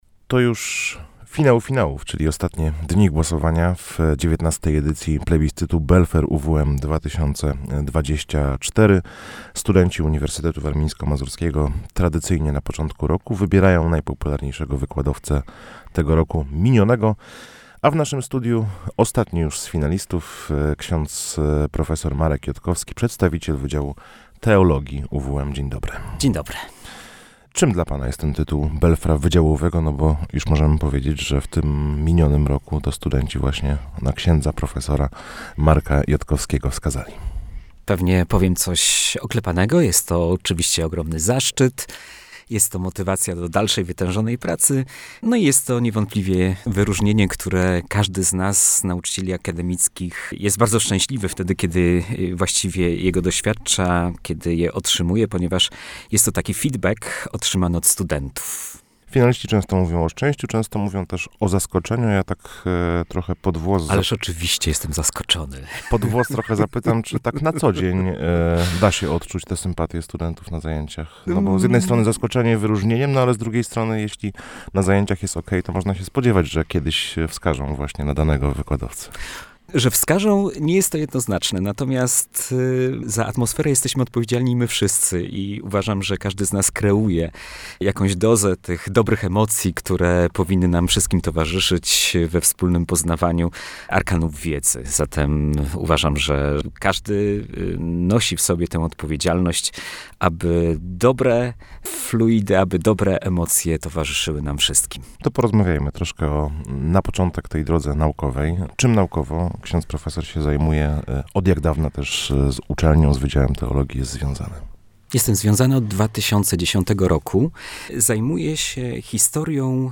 Jakimi zasadami kieruje się w pracy ze studentami? Posłuchajcie naszej rozmowy!